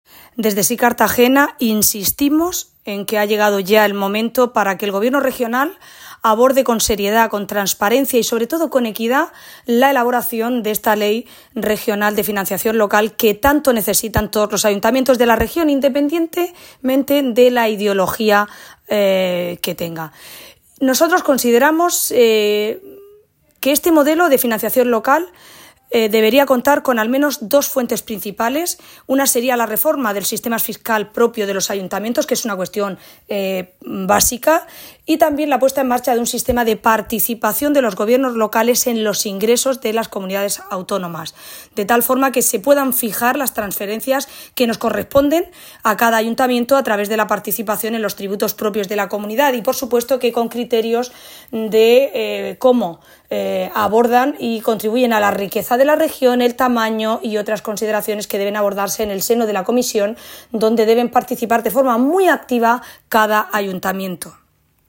Audio: Declaraciones de Ana Bel�n Castej�n. Grupo Mixto (Corte 1) (MP3 - 1,01 MB)